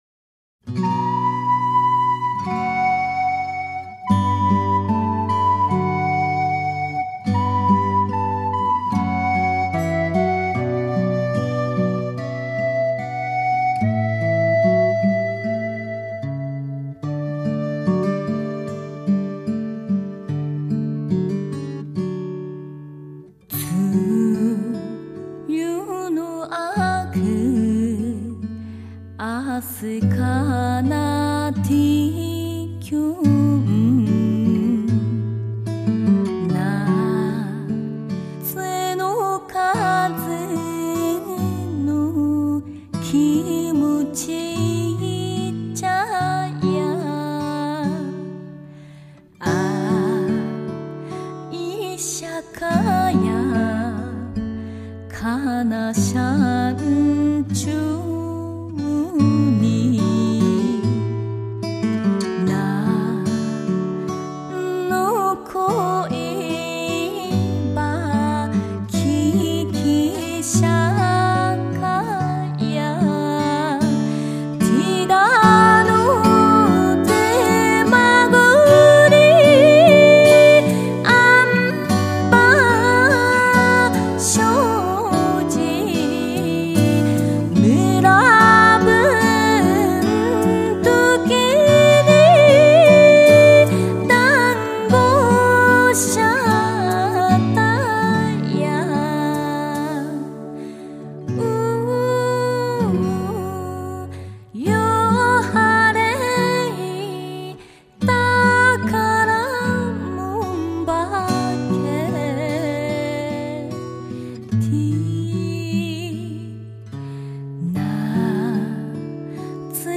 奄美大岛精灵寄宿之声